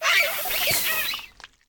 Cri de Tag-Tag dans Pokémon Écarlate et Violet.